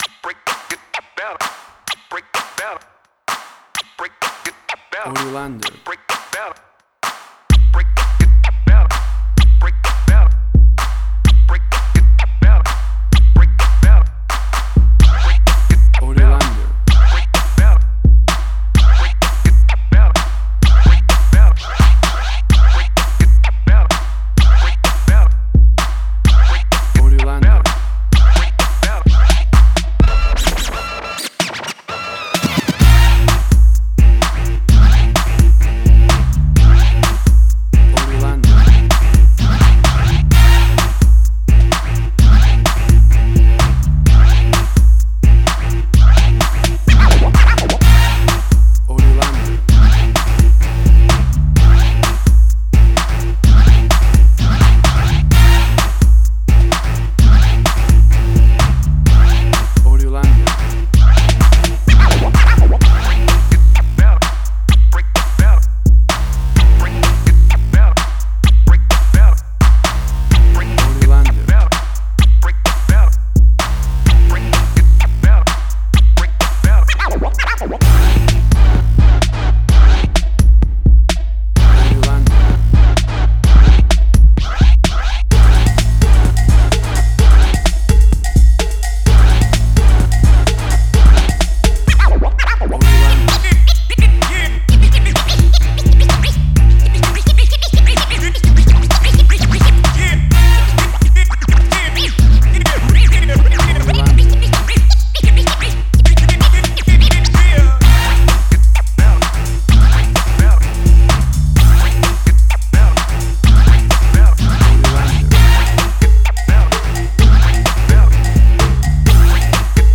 WAV Sample Rate: 16-Bit stereo, 44.1 kHz
Tempo (BPM): 128